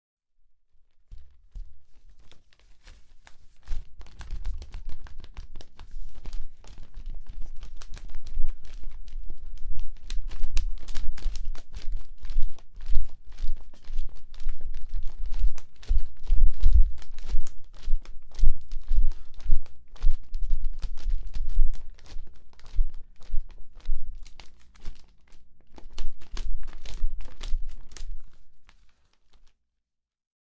描述：在空中移动新闻报纸
Tag: RandomSession 记录 弗利